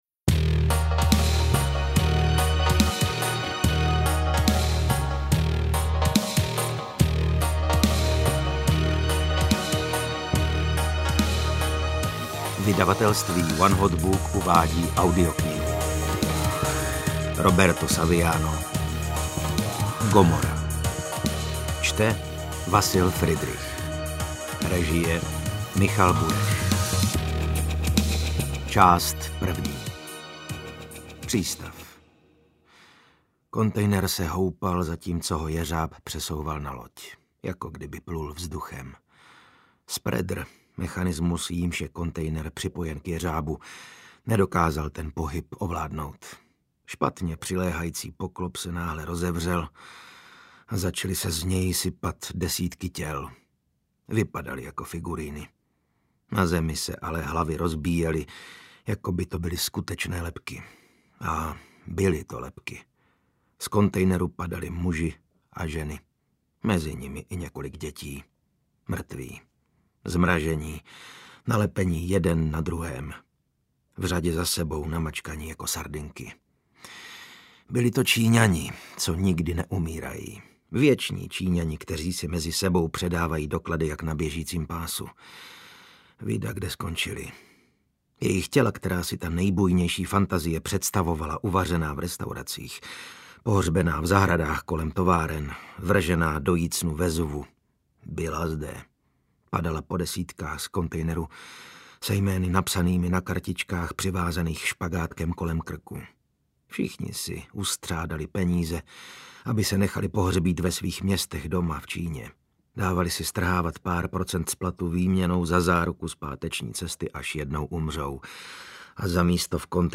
Gomora audiokniha
Ukázka z knihy
• InterpretVasil Fridrich